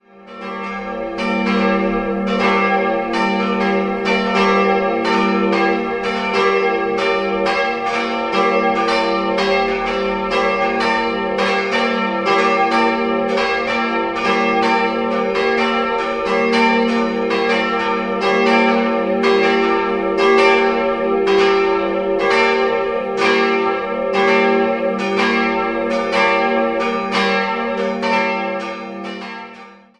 3-stimmiges Geläute: g'-a'(+)-d''